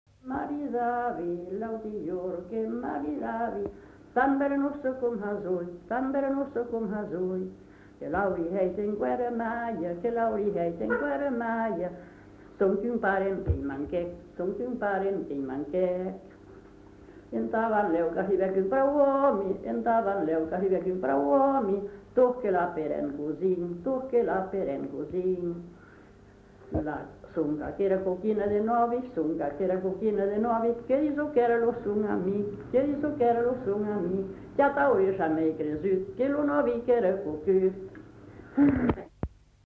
[Brocas. Groupe folklorique] (interprète)
Aire culturelle : Marsan
Genre : chant
Effectif : 1
Type de voix : voix de femme
Production du son : chanté